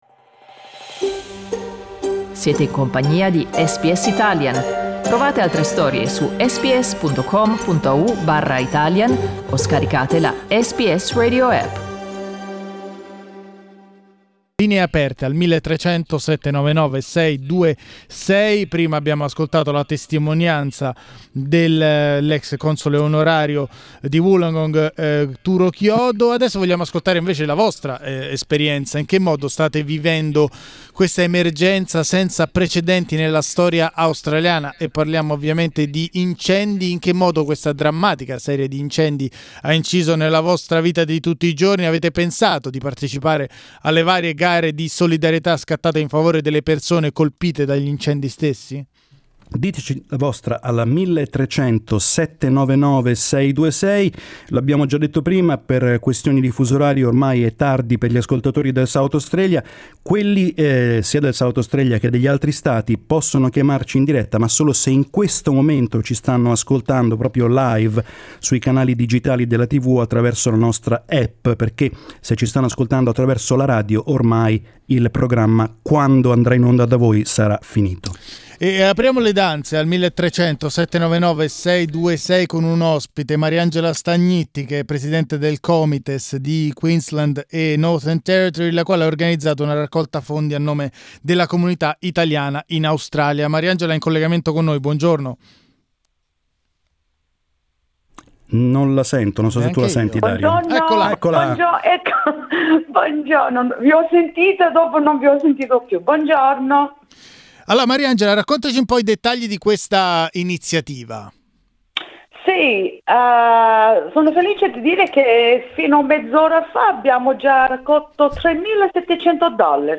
Gli ascoltatori condividono le loro impressioni sulla tragedia senza precedenti nella storia australiana.